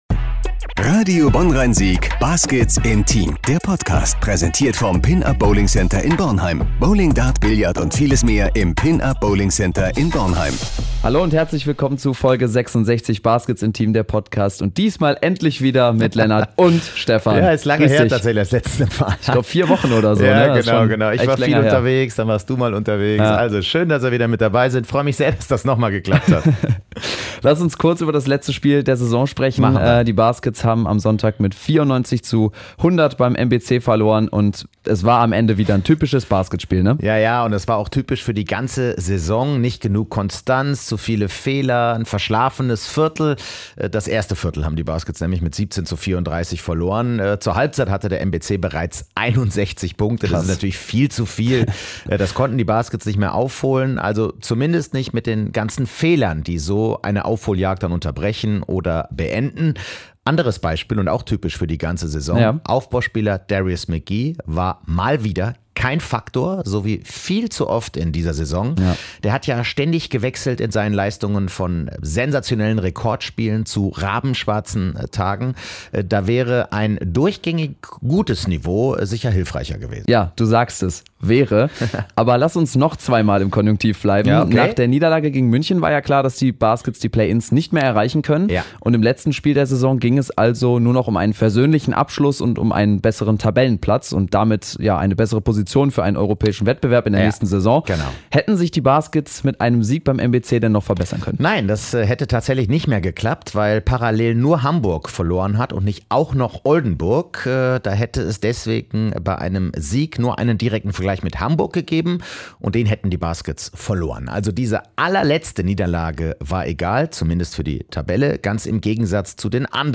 Unser Podcast-Jungs sind endlich wieder vereint im Studio!